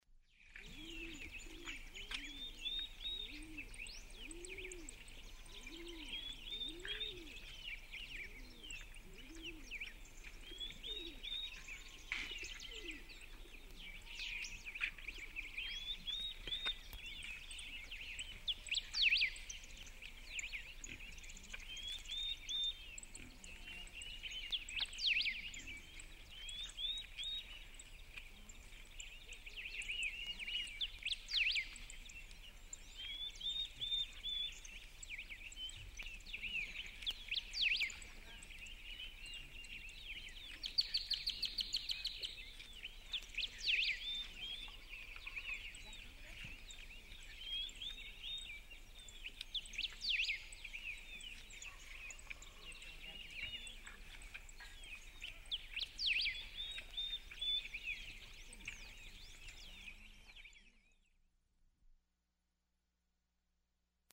AMANECERAMANECER EN EL CAMPO CON AVES34
Tonos EFECTO DE SONIDO DE AMBIENTE de AMANECERAMANECER EN EL CAMPO CON AVES34
Amaneceramanecer_en_el_campo_con_aves34.mp3